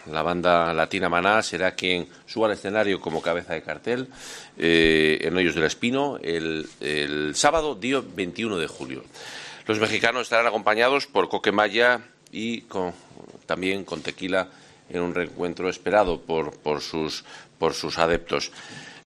El consejero de Fomento y Medio Ambiente, Juan Carlos Suárez-Quiñones
Así lo anunciaba Suarez-Quiñones.